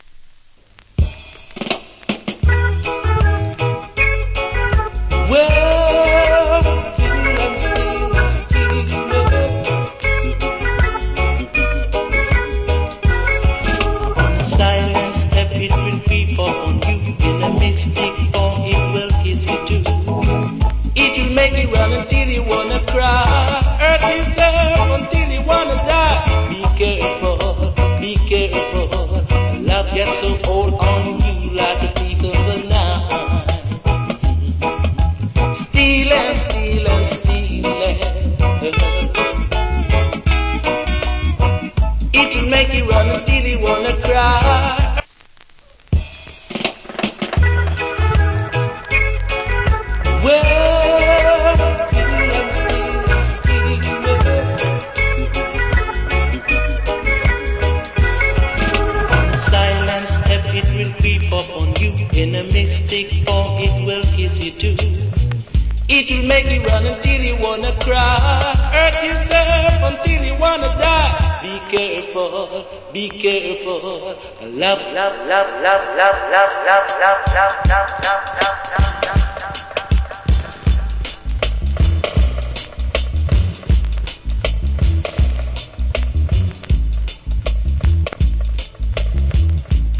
Old Hits Cover. Reggae Vocal.